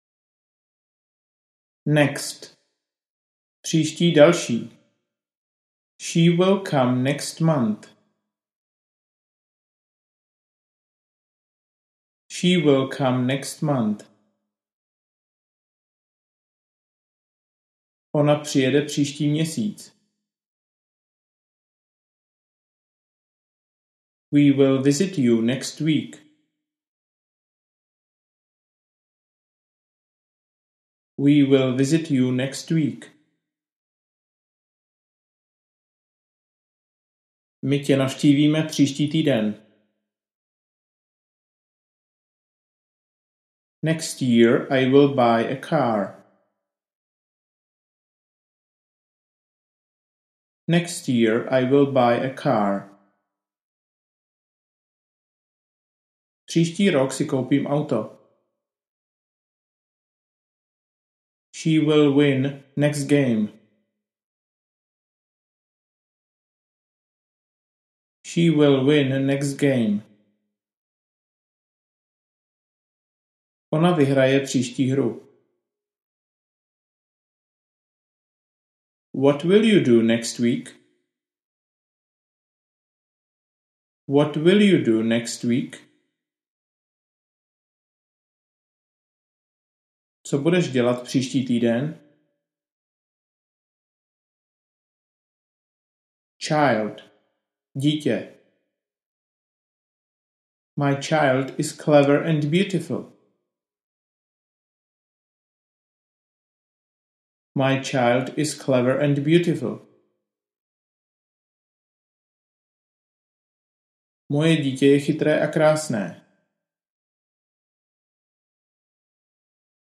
Anglická slovíčka - úroveň 1 pro začátečníky audiokniha
Ukázka z knihy
Za každou anglickou větou je připraven český překlad.